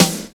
50 SNARE 4.wav